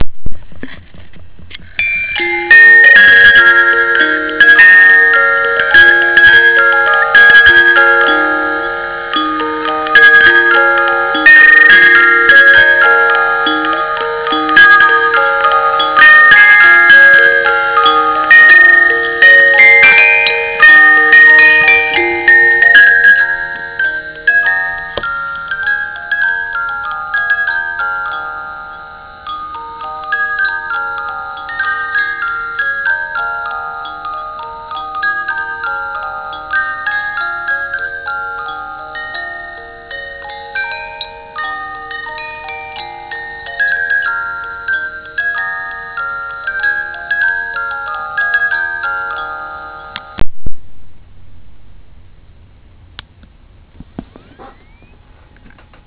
Original Music Box Tune: - >
Repaired Music Box Tune